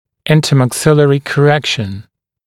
[ˌɪntəmæk’sɪlərɪ kə’rekʃn][ˌинтэмэк’силэри кэ’рэкшн]межчелюстная коррекция